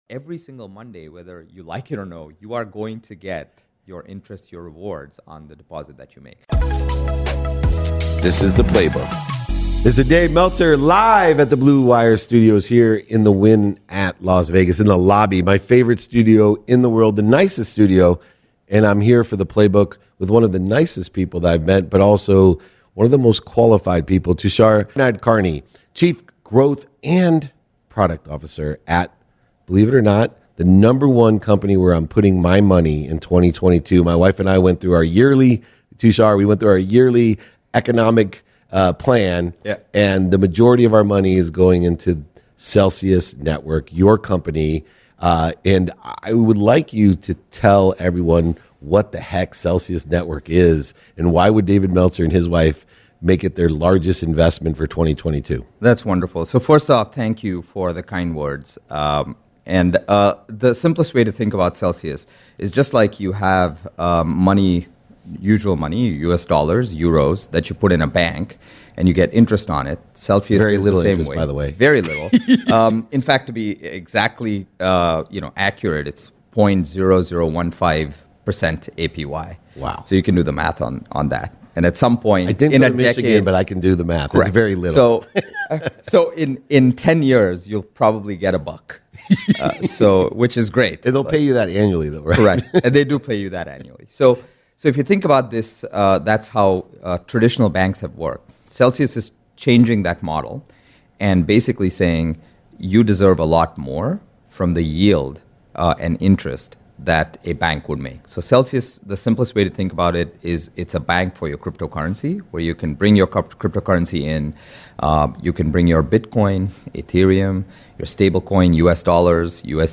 Make More Money From Your Cryptocurrency | Interview With Celsius Network